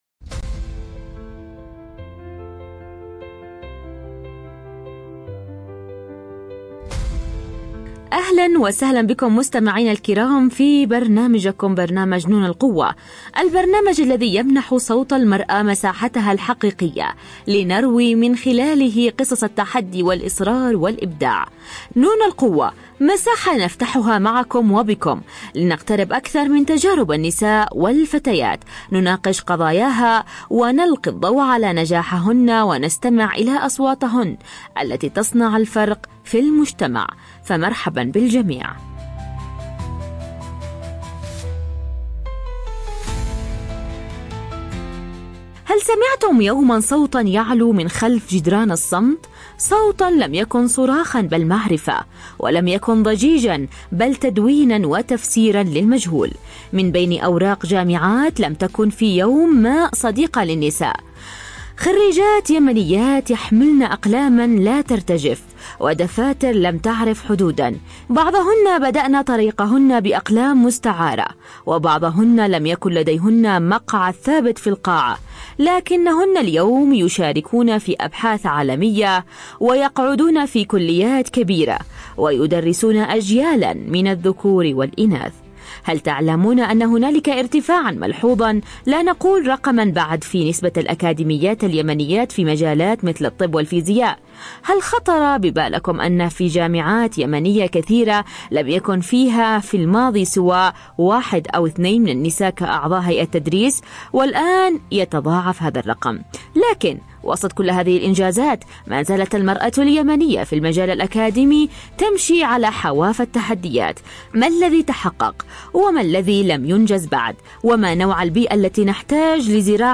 📻 عبر أثير إذاعة رمز